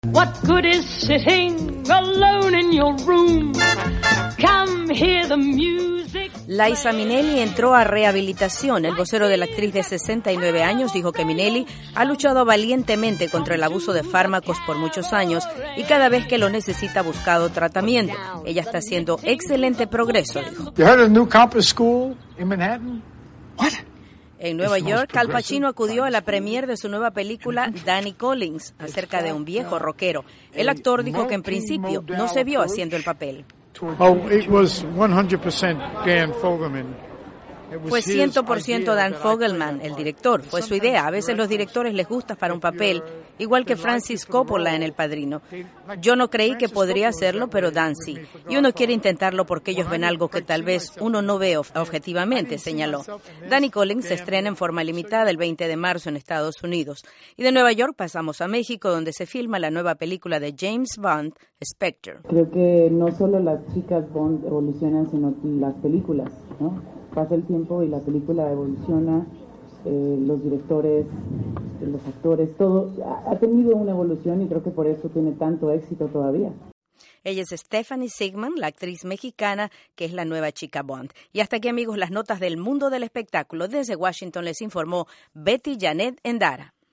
Noticias del Entretenimiento